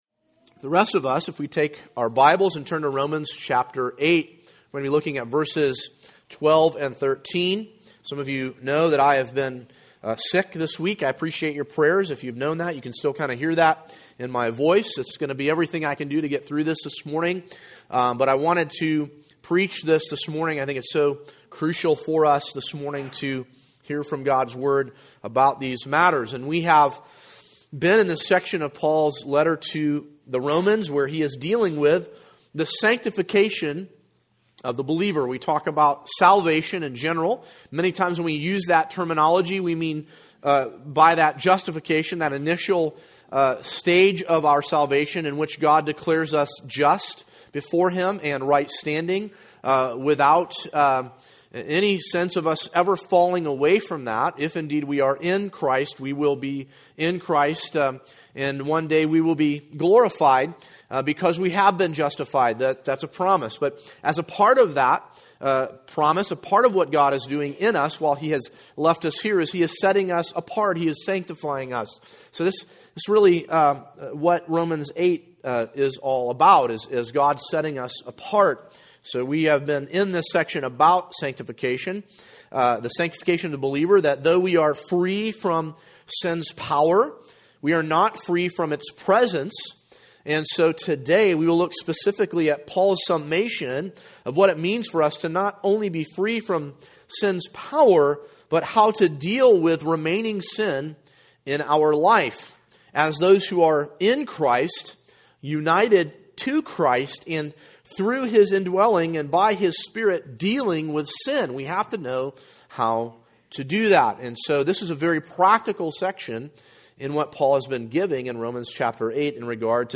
An exposition